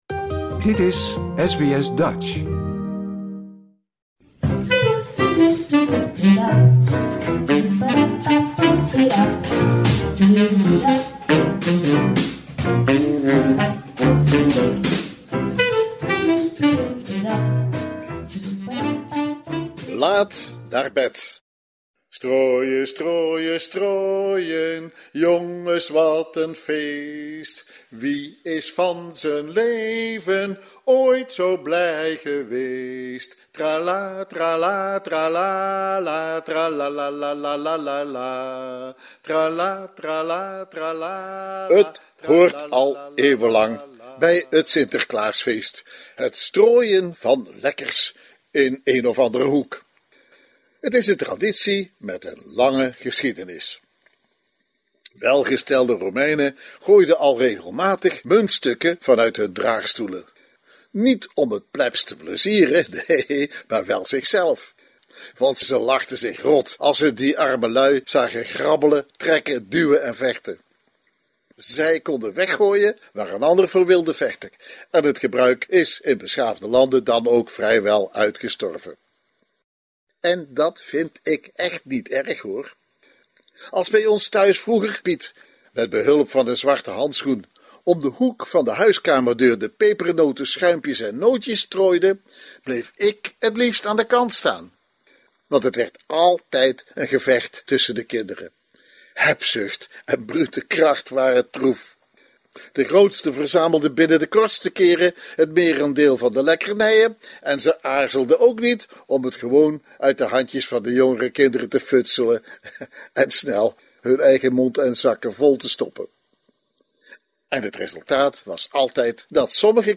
Taalcolumn